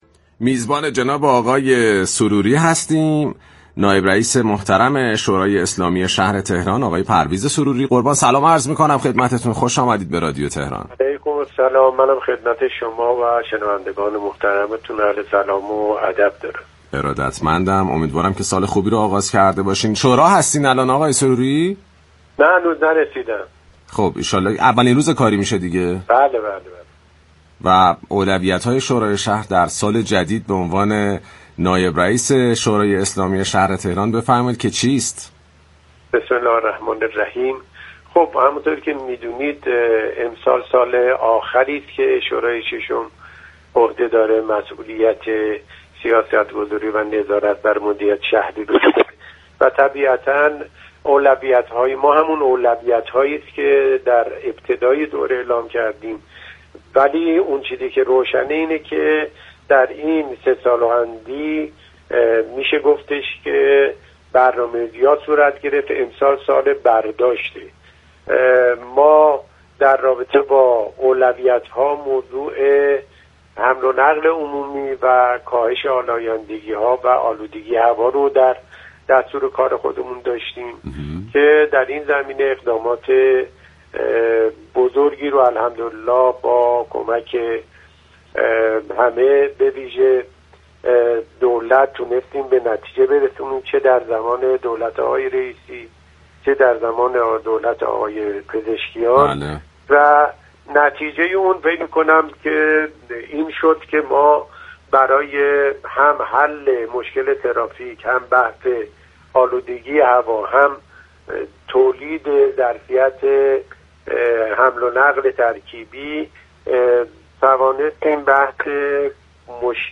به گزارش پایگاه اطلاع رسانی رادیو تهران، پرویز سروری نایب رئیس شورای اسلامی شهر تهران در گفت و گو با برنامه «صبح نو، تهران نو» اظهار داشت: امسال آخرین سال فعالیت ششمین دوره شورای اسلامی شهر تهران است.